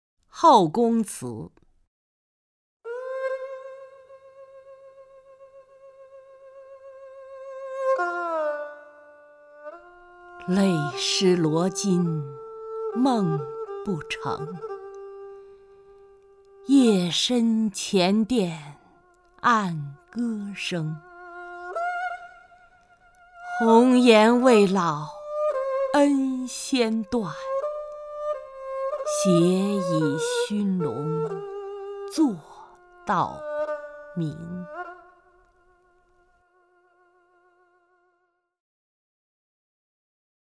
曹雷朗诵：《后宫词》(（唐）白居易) （唐）白居易 名家朗诵欣赏曹雷 语文PLUS